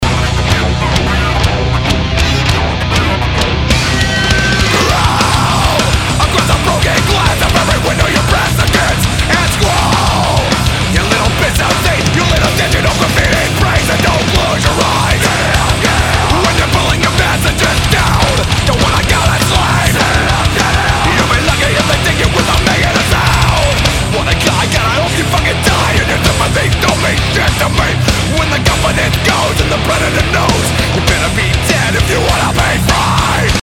• Качество: 320, Stereo
heavy Metal
Жанр: Alternative metal